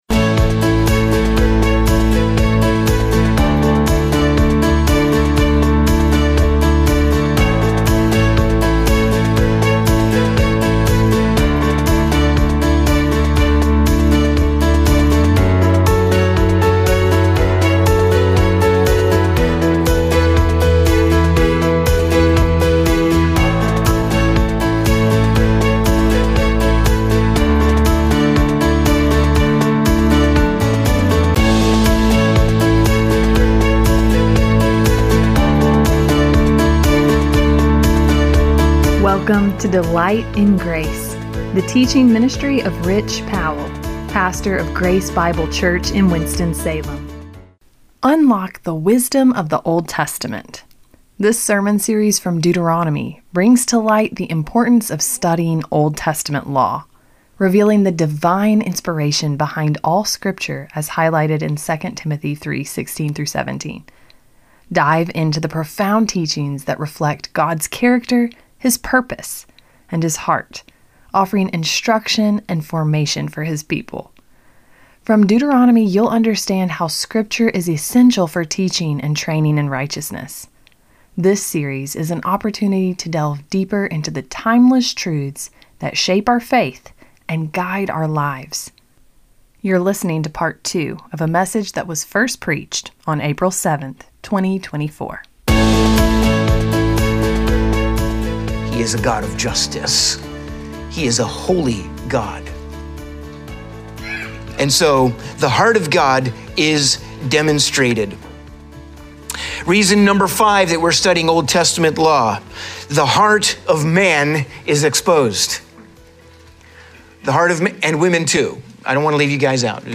This sermon series from Deuteronomy brings to light the importance of studying Old Testament law, revealing the divine inspiration behind all Scripture as highlighted in 2 Timothy 3:16-17.